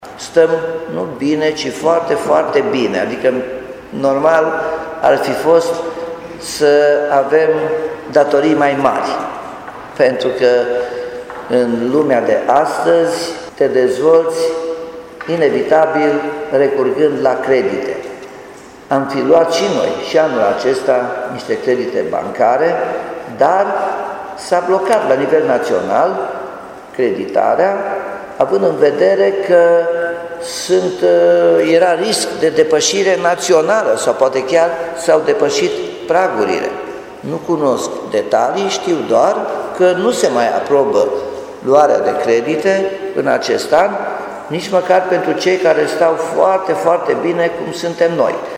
Municipalitatea stă bine la gradul de îndatorare, spune primarul Nicolae Robu